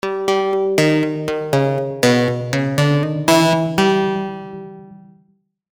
flip with square shape